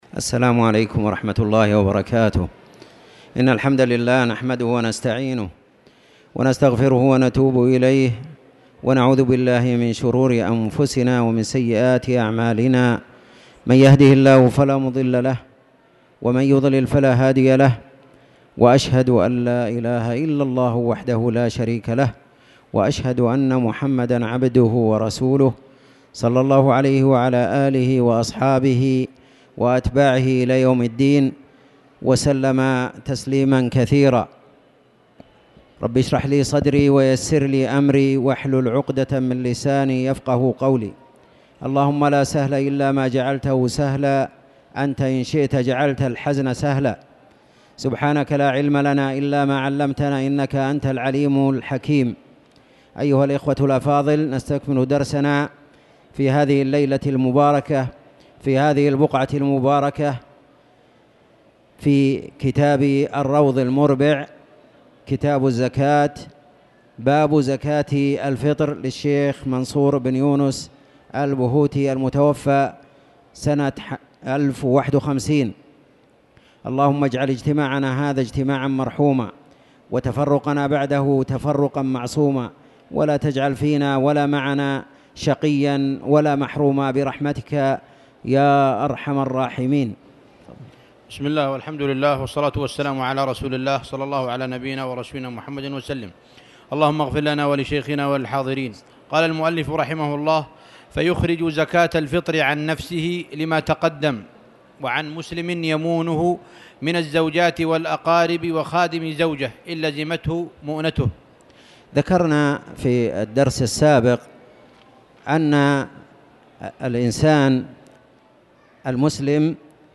تاريخ النشر ٢٣ محرم ١٤٣٨ هـ المكان: المسجد الحرام الشيخ